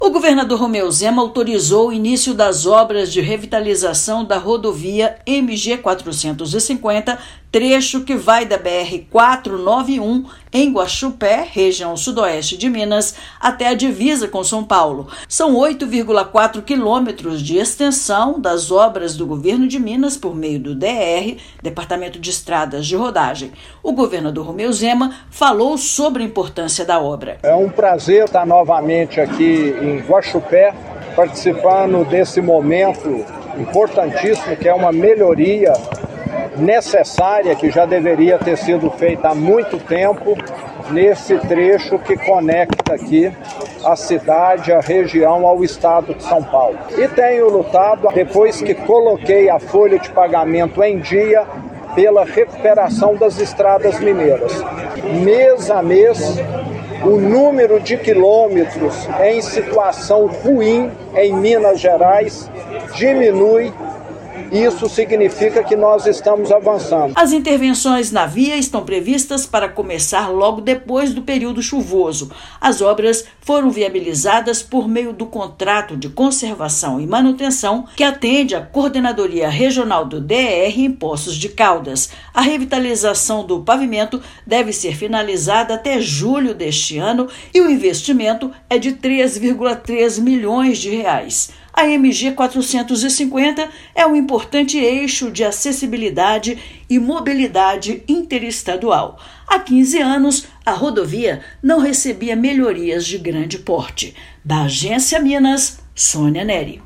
Importante eixo de transporte da região não recebia ações de recuperação de pavimento há mais de uma década. Ouça matéria de rádio.